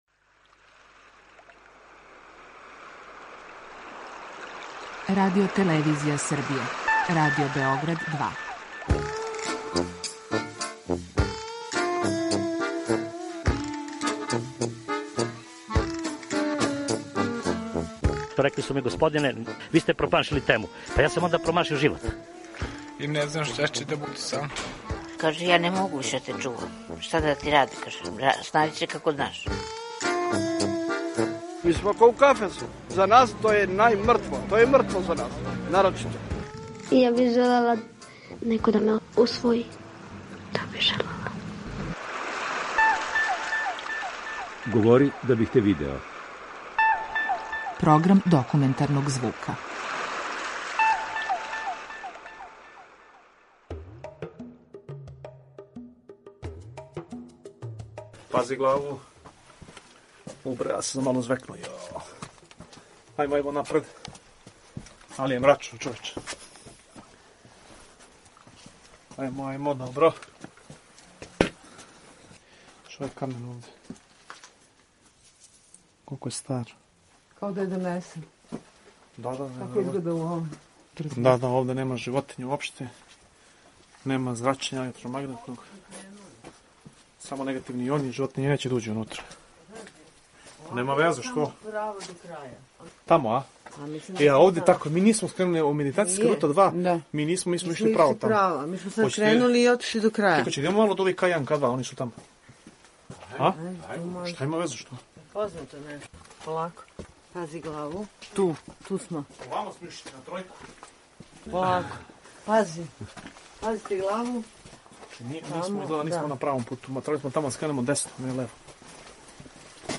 Документарни програм
Тунели Равне у Високом простиру се на два и по километра и дубоки су 300 метара. Дубоко у земљи групе туриста мимоилазе се у тесним пролазима, уз пратњу водича који причају на немачком, енглеском, чешком, пољском језику...